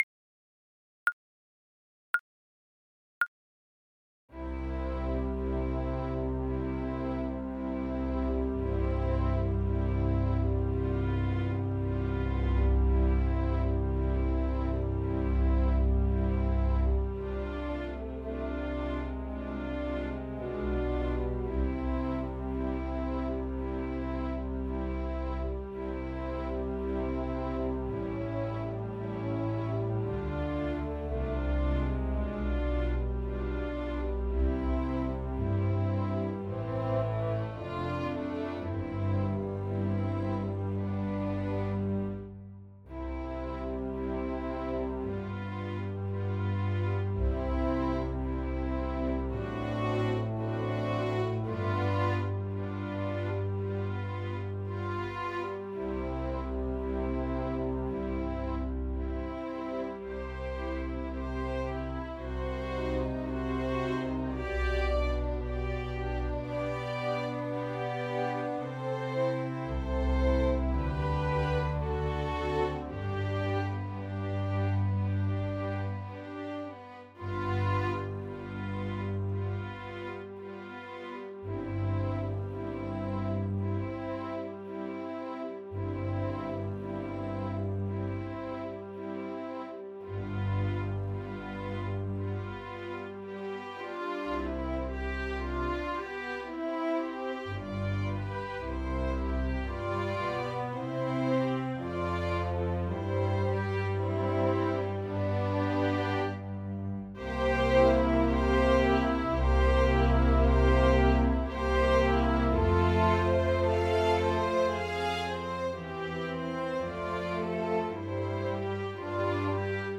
4/4 (View more 4/4 Music)
C major (Sounding Pitch) D major (Clarinet in Bb) (View more C major Music for Clarinet )
Classical (View more Classical Clarinet Music)